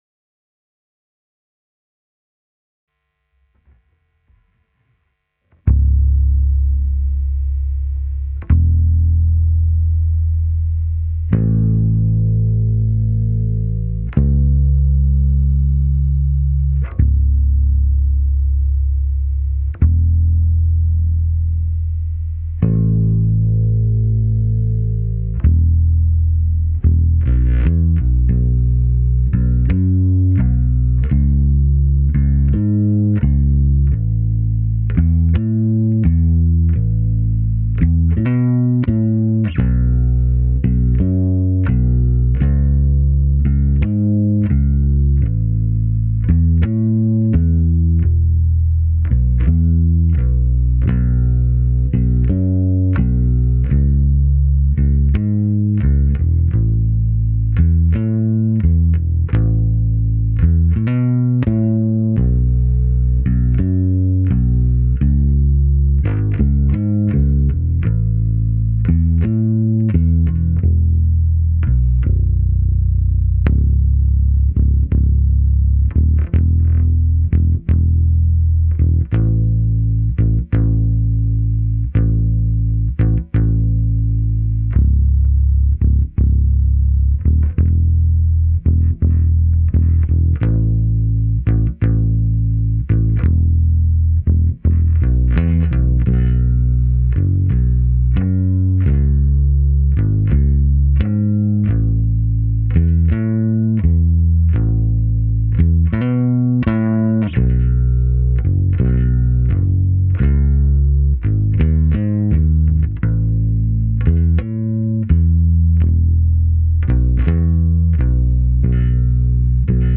Pop Ballade